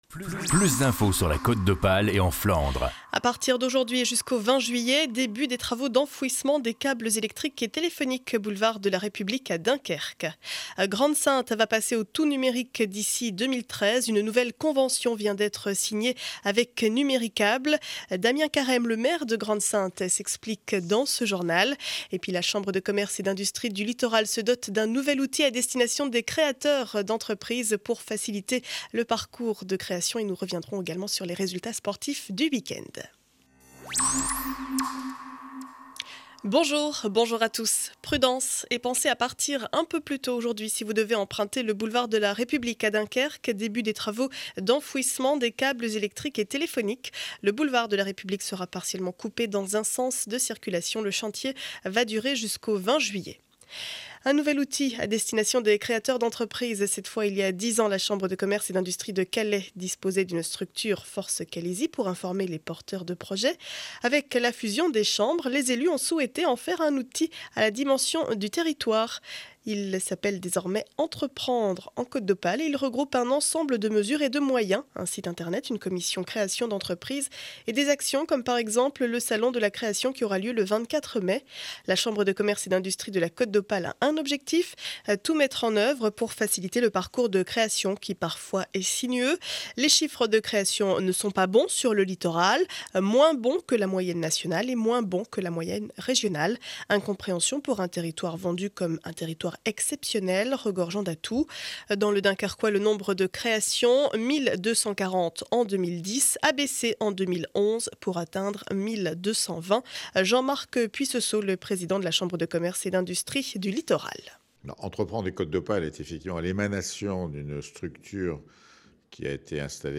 Journal du lundi 02 avril 2012 7 heures 30 édition du Dunkerquois.